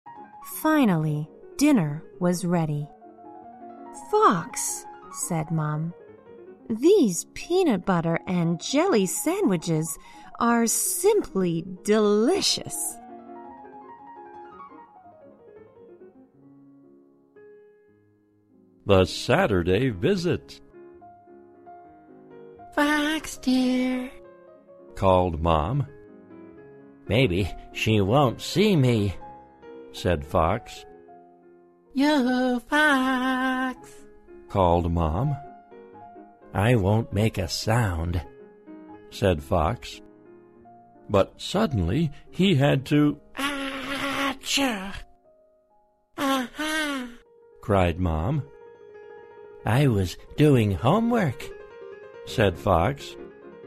在线英语听力室小狐外传 第24期:花生酱加果酱三明治的听力文件下载,《小狐外传》是双语有声读物下面的子栏目，非常适合英语学习爱好者进行细心品读。故事内容讲述了一个小男生在学校、家庭里的各种角色转换以及生活中的趣事。